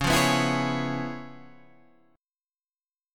C#7#9 chord {9 8 9 9 9 9} chord